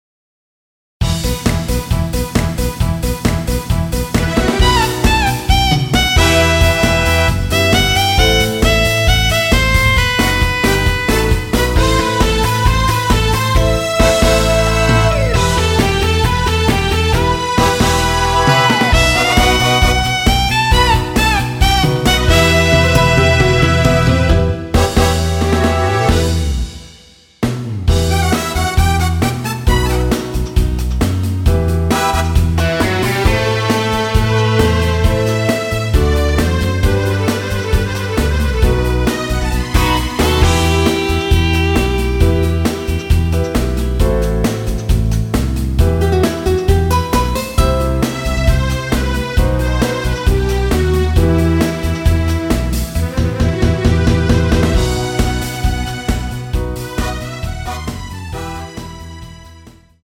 Em
◈ 곡명 옆 (-1)은 반음 내림, (+1)은 반음 올림 입니다.
앞부분30초, 뒷부분30초씩 편집해서 올려 드리고 있습니다.
중간에 음이 끈어지고 다시 나오는 이유는